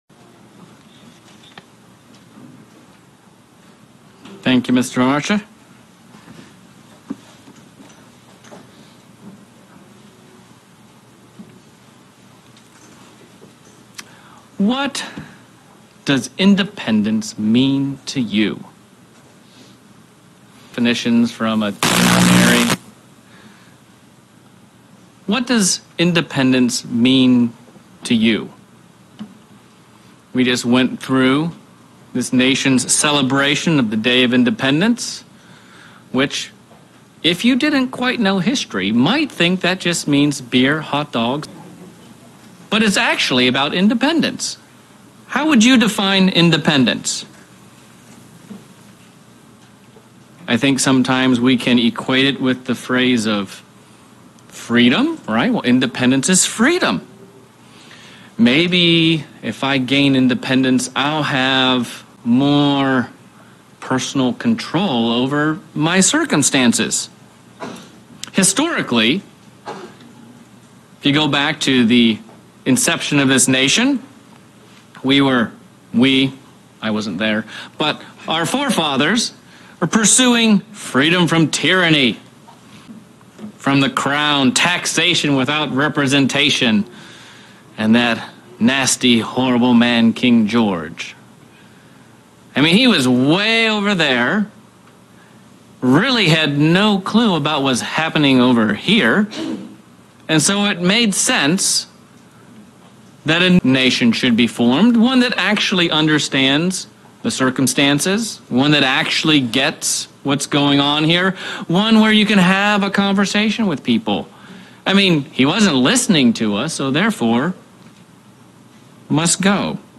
Fourth of July sermon focusing on what independence really is.